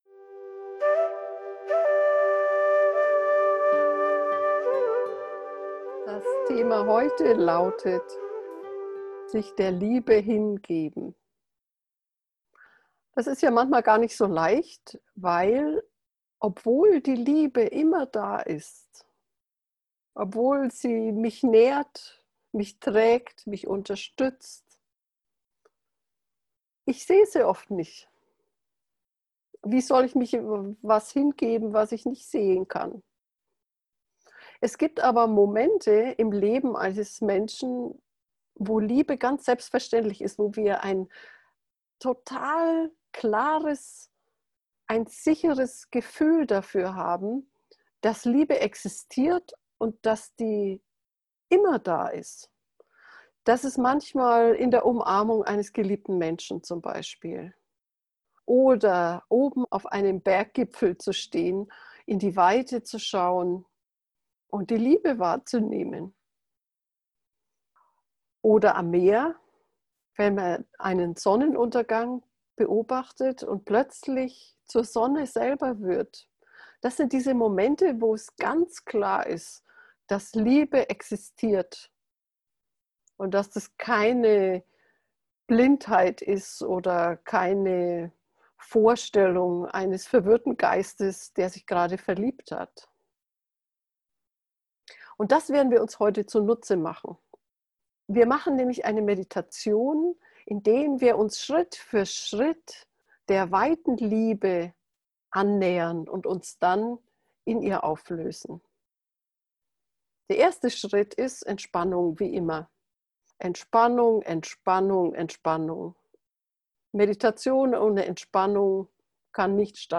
Folge 31: Sich der Liebe hingeben – geführte Herzmeditation vor dem Einschlafen - FindYourNose